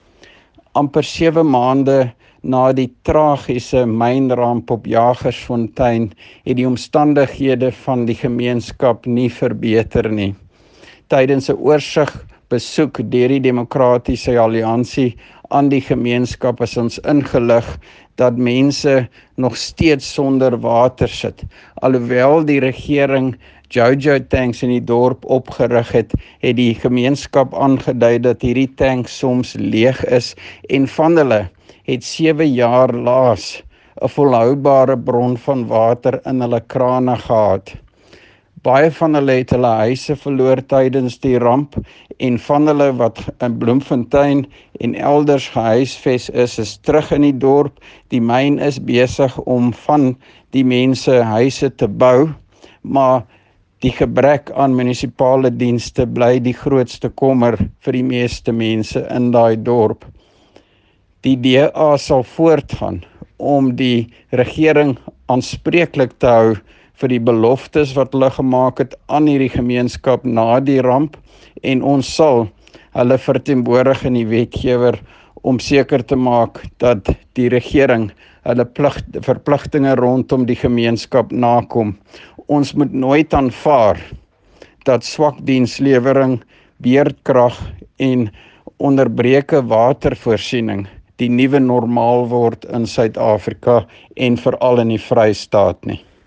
Afrikaans soundbites by Roy Jankielsohn MPL with images here, here and here